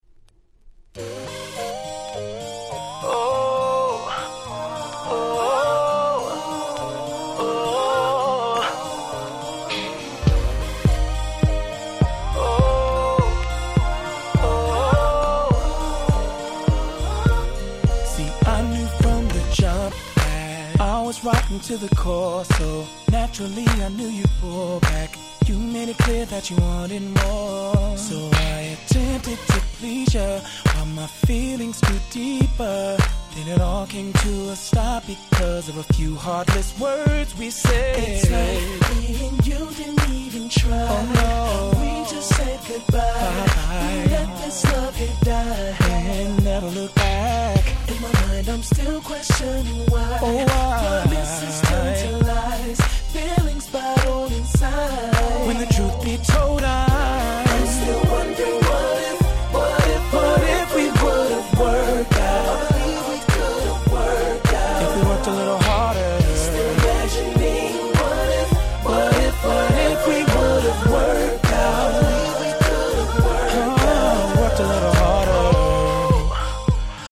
09' Nice R&B Compilation !!